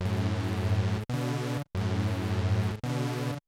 RAVE CHORD-L.wav